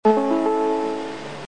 チャイム４
高速バスの起終点や途中休憩ではチャイム１〜３が流れ（※テープの車は１種類のみで全て流れるのは音声合成装置の車）、その他の放送や一般路線バスではチャイム４が流れます。
aizubus4.mp3